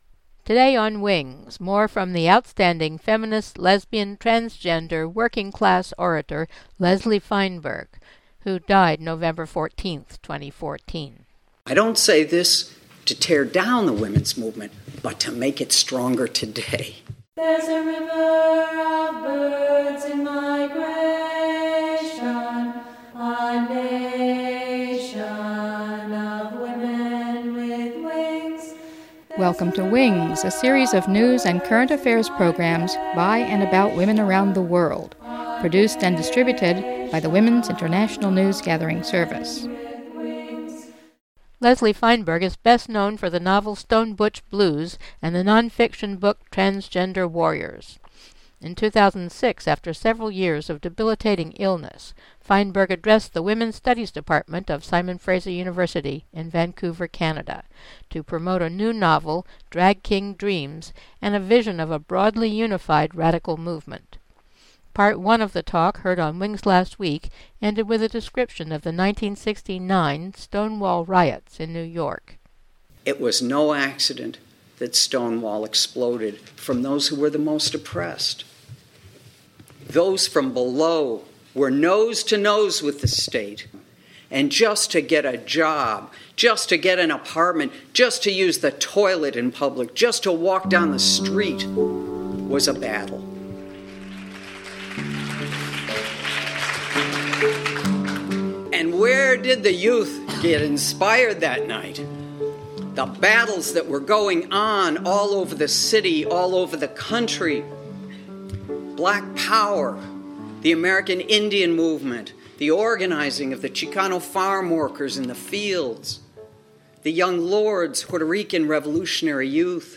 Part 2 of speech by the late transgender feminist